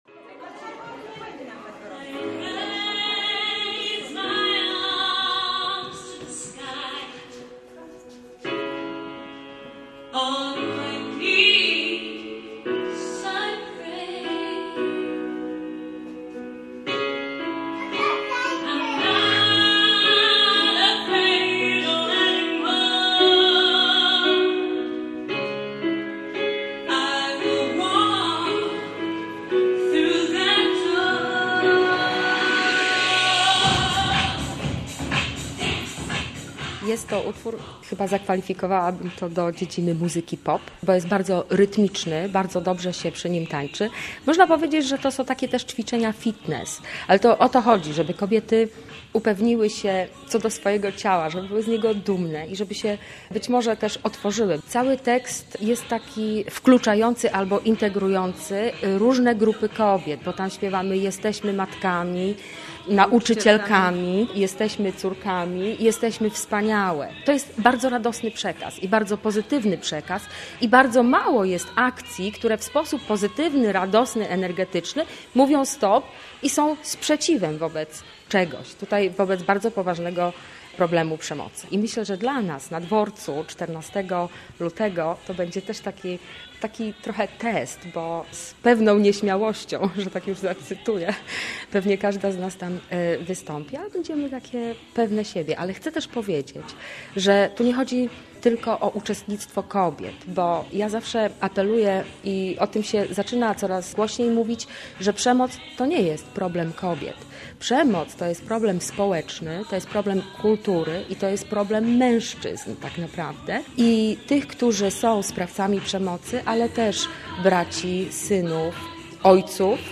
Miliard - reportaż